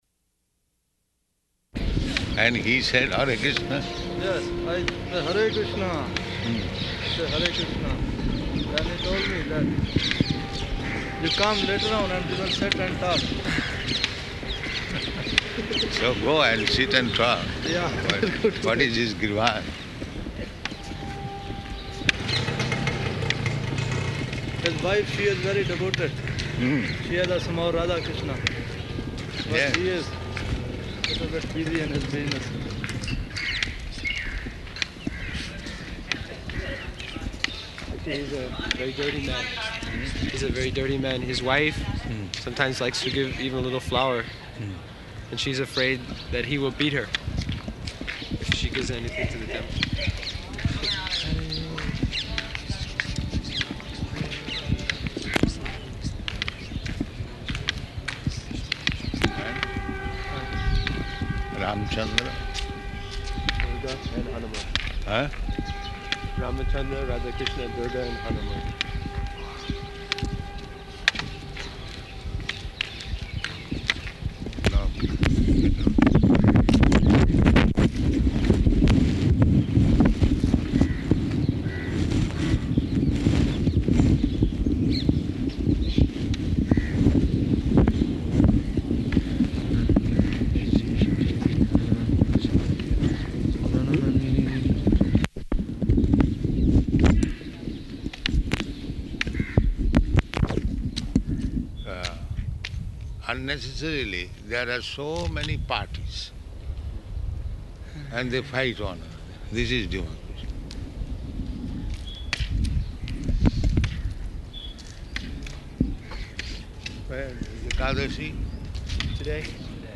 Morning Walk --:-- --:-- Type: Walk Dated: November 29th 1975 Location: Delhi Audio file: 751129MW.DEL.mp3 Prabhupāda: And he said "Hare Kṛṣṇa"?